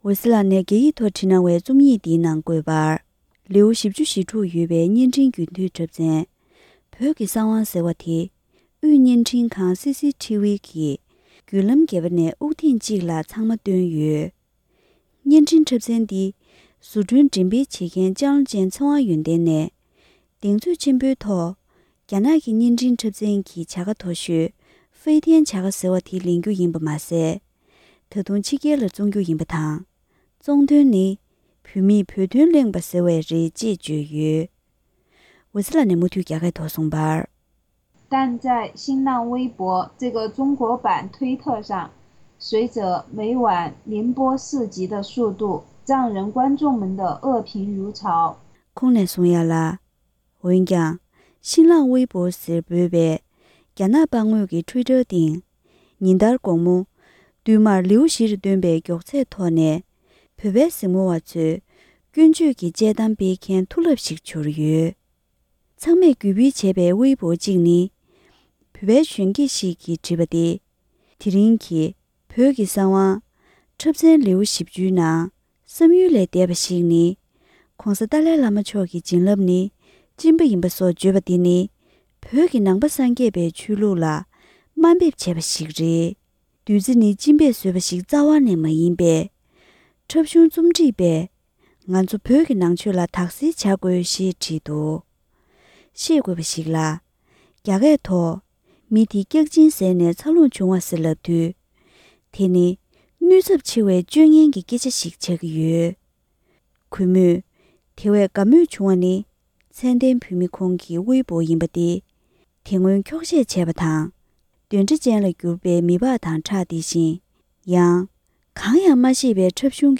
ཕབ་བསྒྱུར་དང་སྙན་སྒྲོན་ཞུས་པར་གསན་རོགས་ཞུ༎